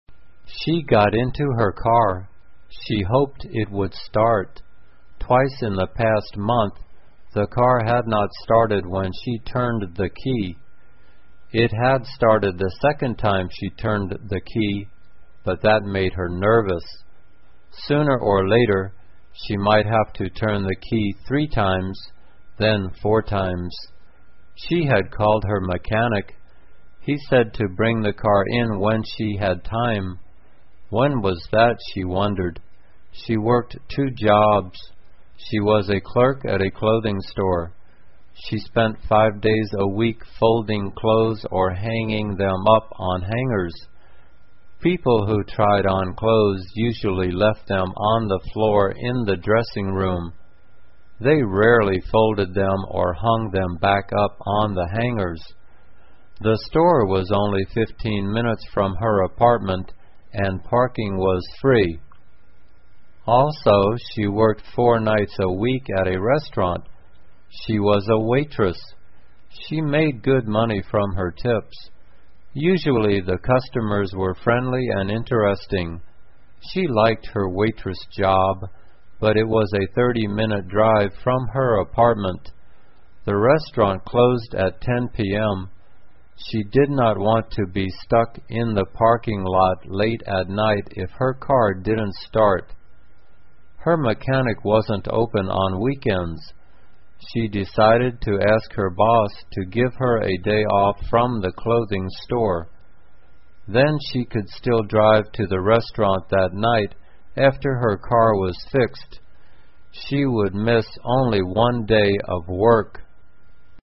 慢速英语短文听力 修车 听力文件下载—在线英语听力室